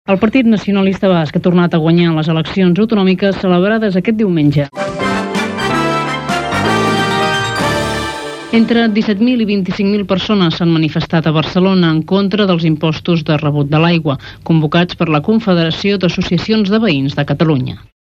Butlletí informatiu - Ràdio 4, 1980's